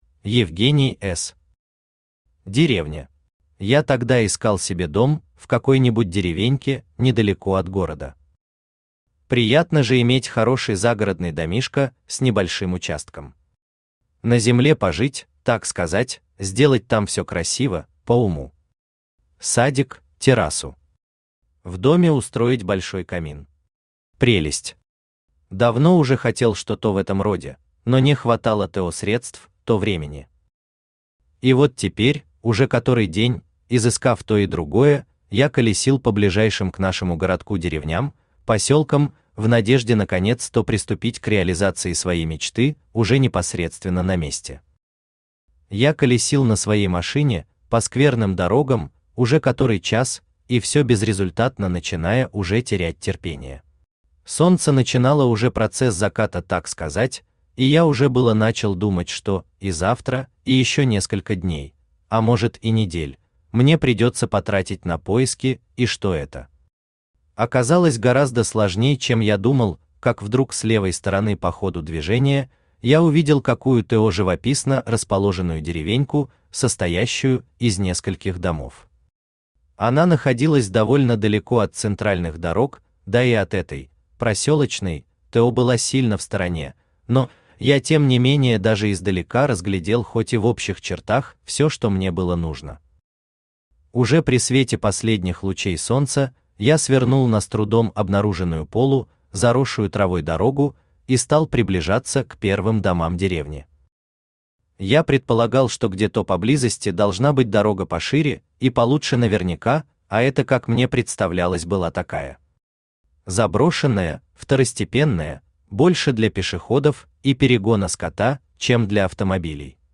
Aудиокнига Деревня Автор Евгений С. Читает аудиокнигу Авточтец ЛитРес.